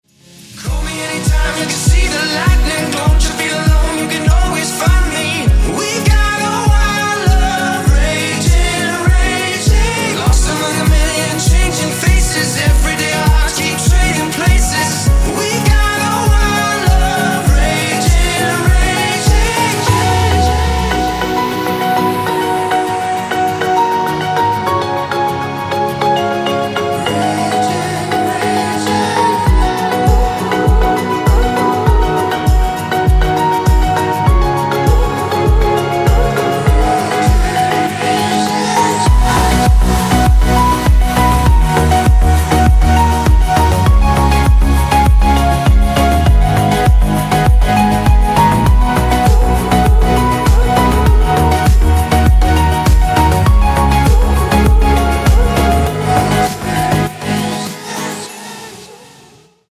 • Качество: 128, Stereo
мужской вокал
dance
vocal